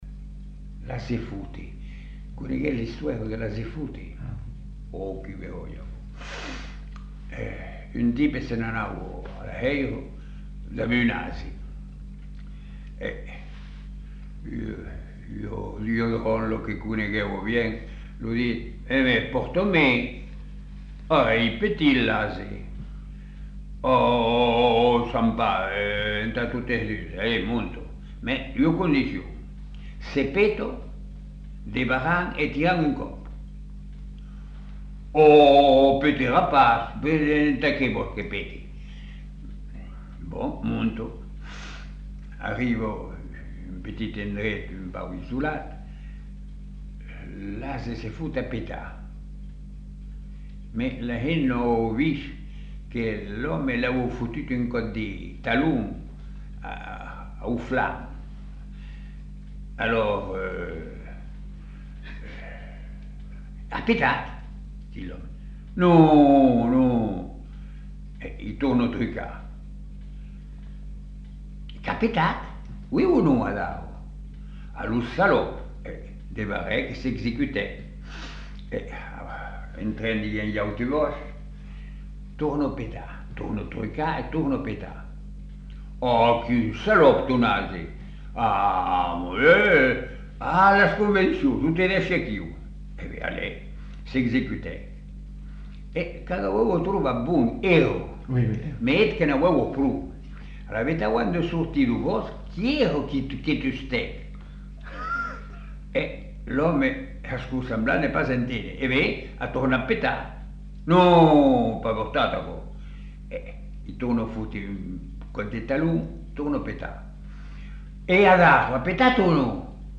Lieu : Masseube
Genre : conte-légende-récit
Effectif : 1
Type de voix : voix d'homme
Production du son : parlé
Classification : conte facétieux